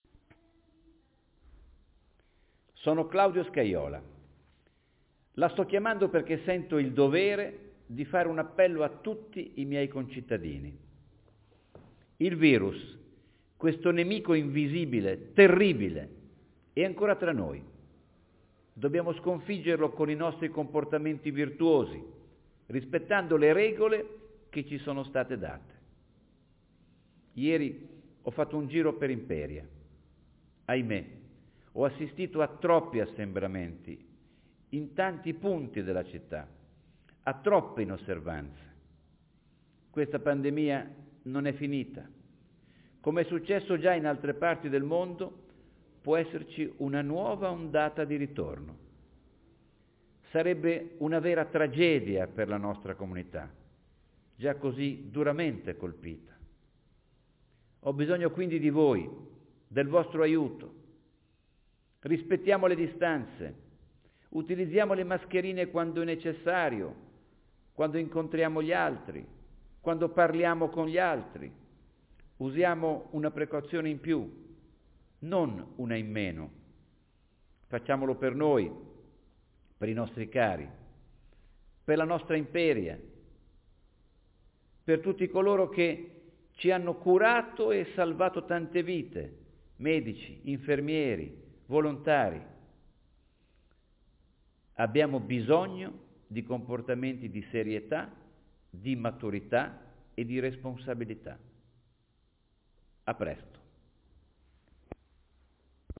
Il sindaco Scajola telefona ai cittadini
Così inizia una telefonata del sindaco Claudio Scajola ai cittadini, che grazie al nuovo servizio di comunicazione "Sindaci in Contatto 2.0", ha inviato un messaggio registrato (di circa due minuti) a tutti gli utenti iscritti, sottolineando l'importanza di rispettare le regole, per evitare un nuovo diffondersi dei contagi.